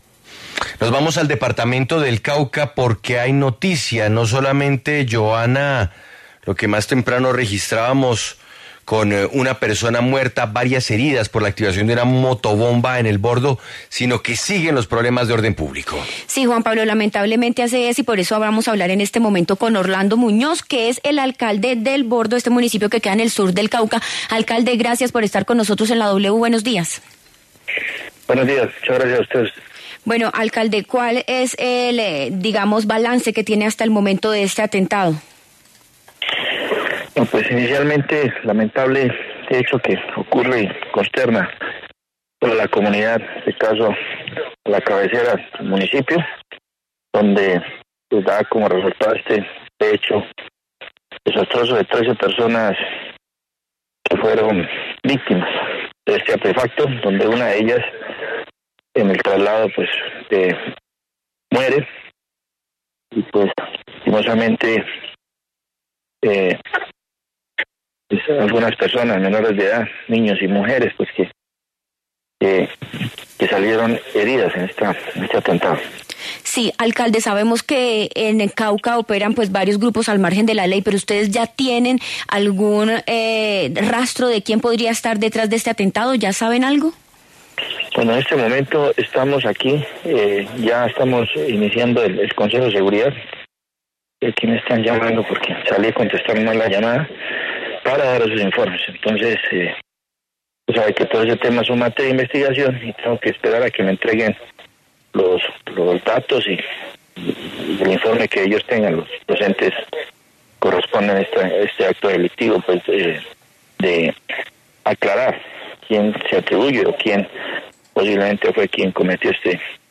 En La W, el alcalde Orlando Muñoz se pronunció sobre el hombre que falleció en un centro médico en Popayán, a donde fue remitido por la gravedad de las heridas.